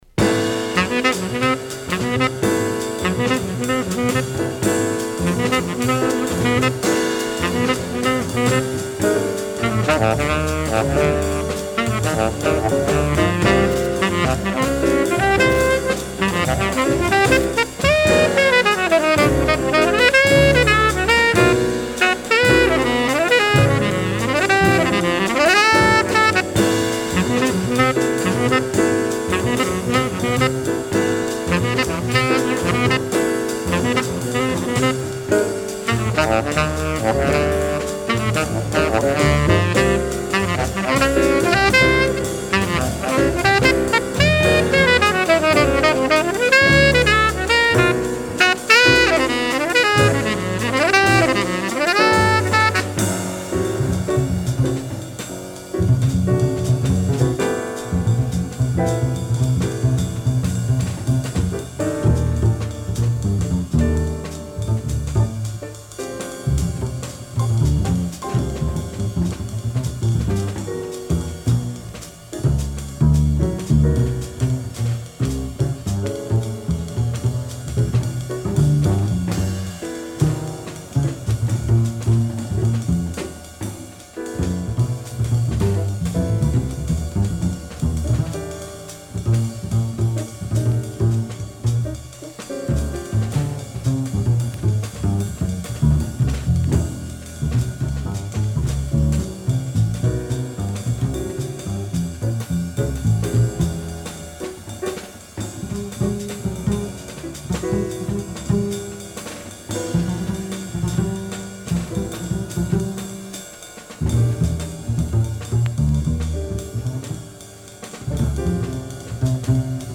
Original mono pressing